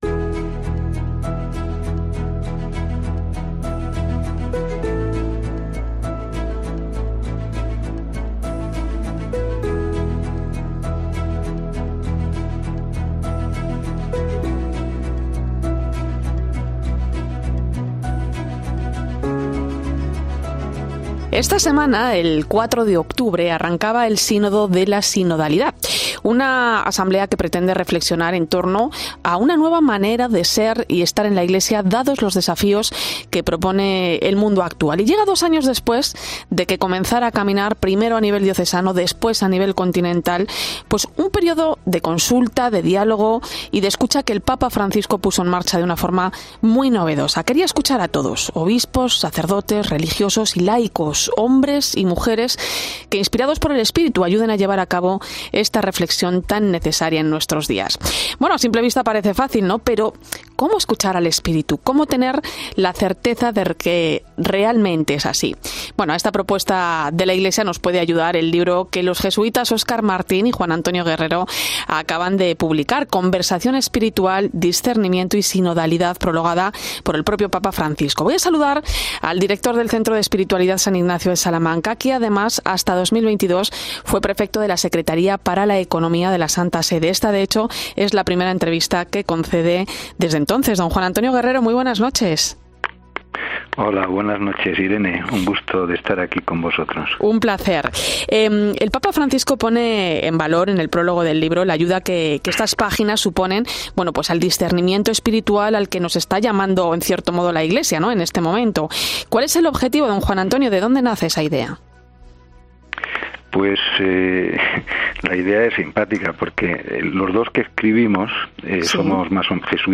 En su primera entrevista tras su etapa como prefecto de la Secretaría para la Economía de la Santa Sede, el jesuita reflexiona sobre la sinodalidad a...